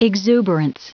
Prononciation du mot exuberance en anglais (fichier audio)
Prononciation du mot : exuberance